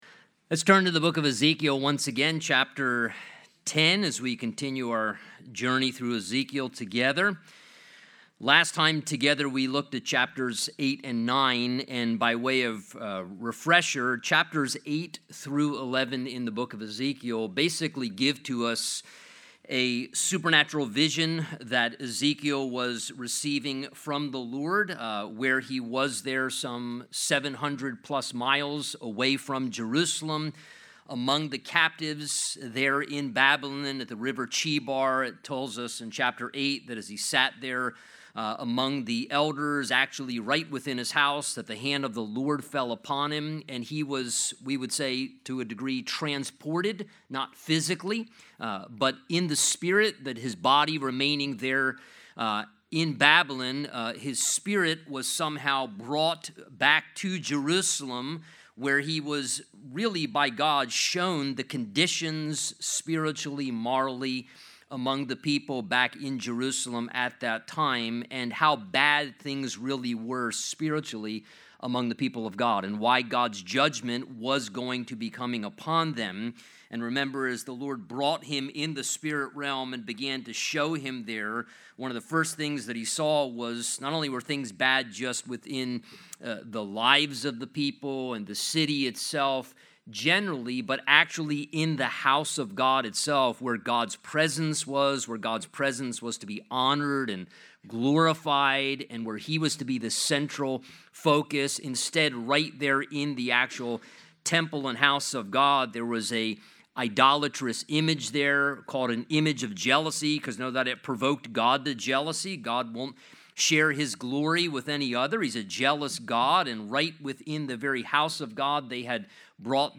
Midweek services